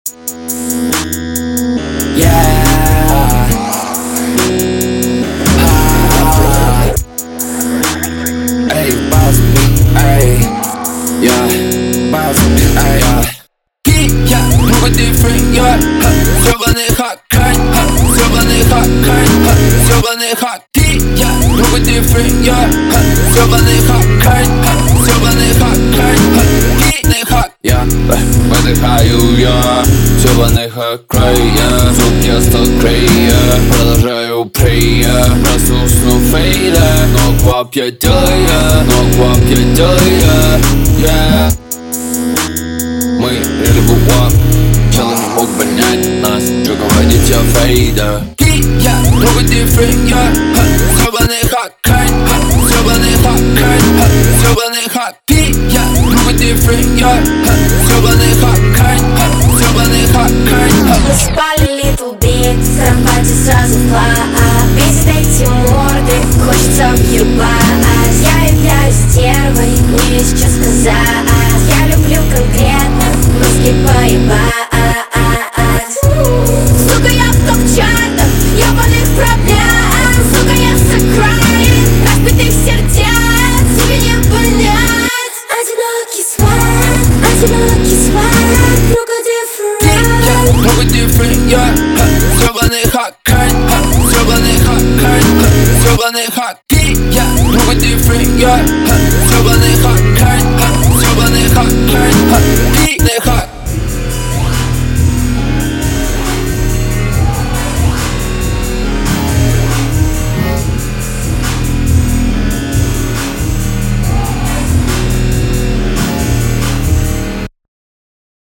Качество: 320 kbps, stereo
Рэп, Новинки музыки в пятницу